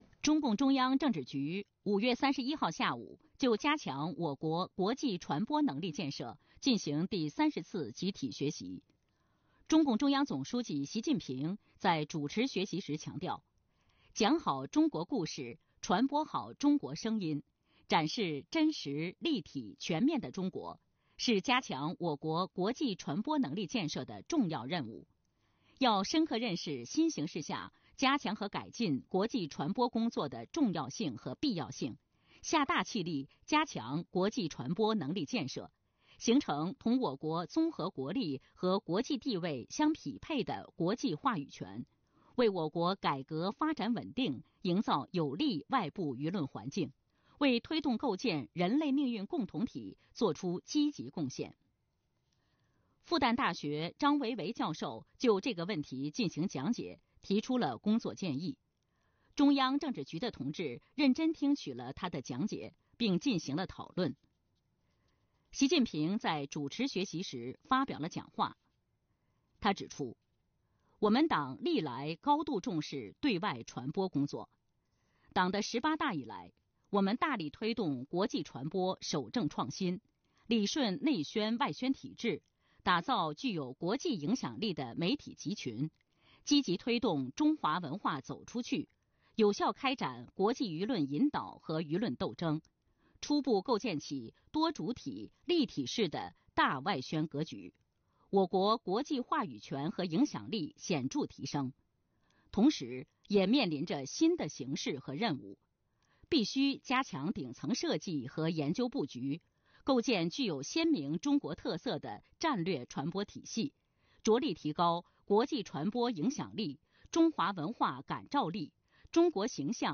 视频来源：CCTV1《新闻联播》